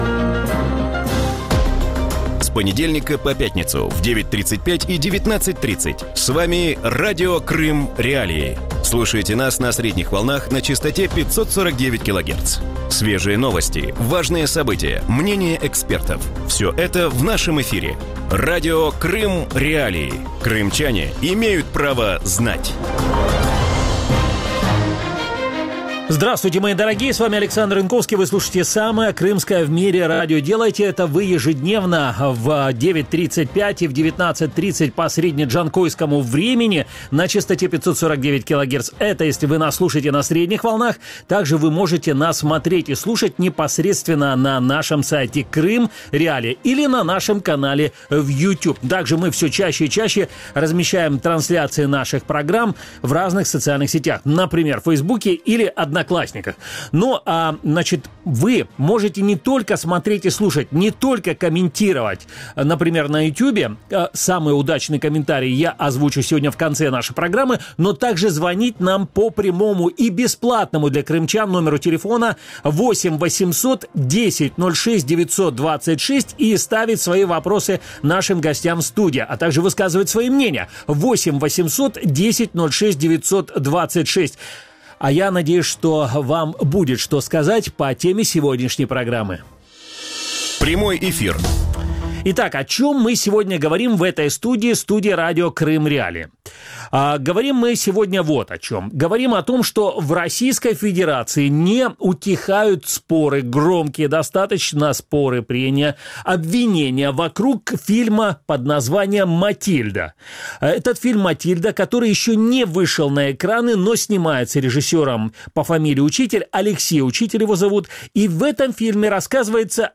У вечірньому ефірі Радіо Крим.Реалії говорять про наполегливе бажання екс-прокурора анексованого Криму, депутата Державної думи Наталії Поклонської заборонити до показу фільм Олексія Учителя «Матильда» про життя імператора Миколи II. Що стоїть за прагненням окремих російських депутатів не допустити «фальсифікацію історії» і як державна політика в Росії впливає на сферу культури?